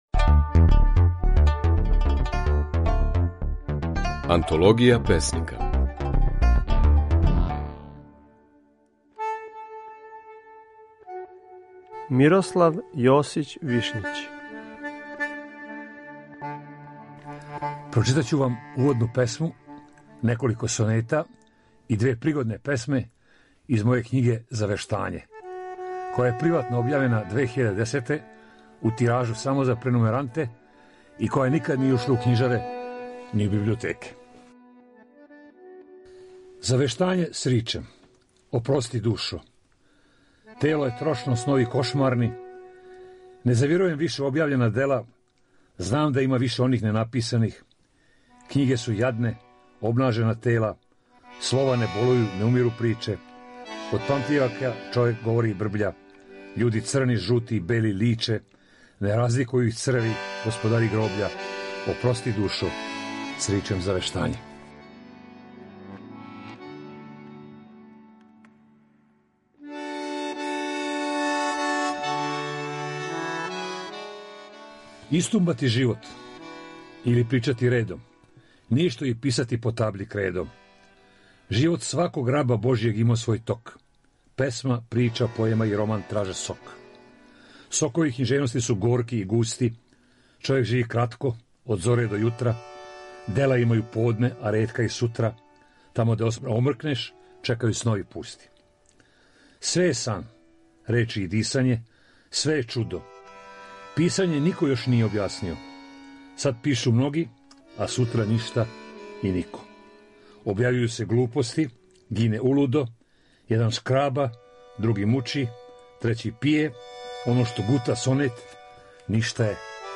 Можете чути како своје стихове говори књижевник Мирослав Јосић Вишњић (1946-2015)